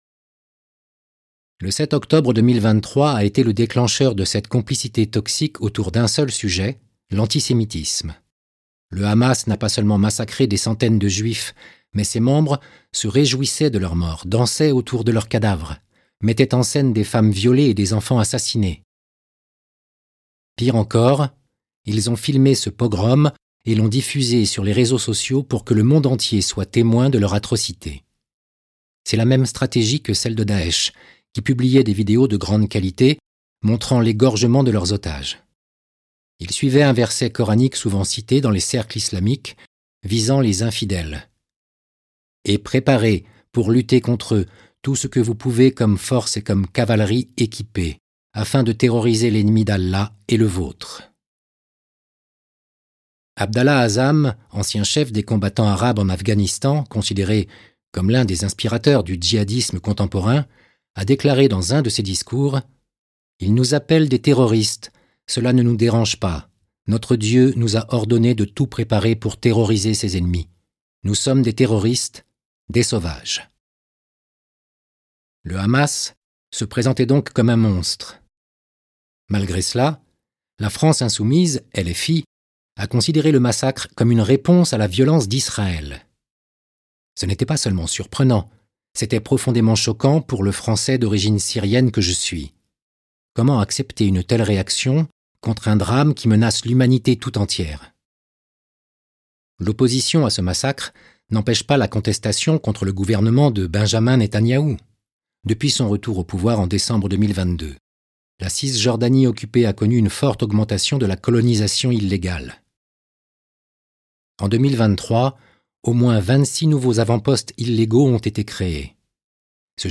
Livre audio: Les complices du mal de Omar Youssef Souleimane